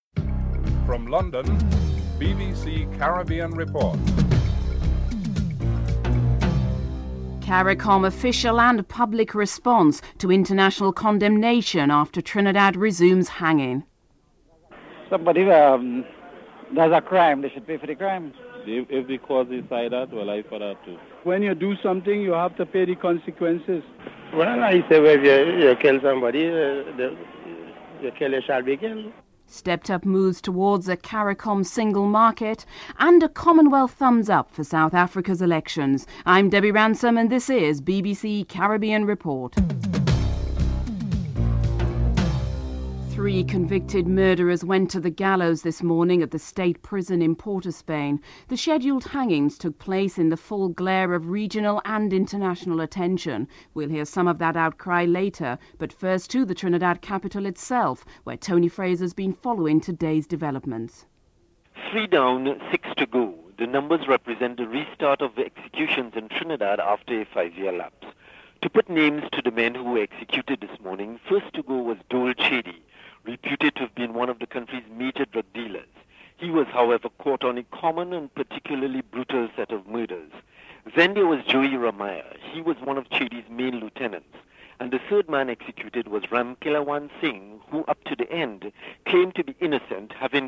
An appeal to settle outstanding CARICOM debt was one of the issues addressed at a CARICOM Trade Ministers meeting in Georgetown, Guyana. CARICOM Secretary General Edwin Carrington discusses the non-implementation of agricultural programmes in the Caribbean and other major issues.
Team Chairman Sir David Steele discusses the elections (13:04 - 15:25)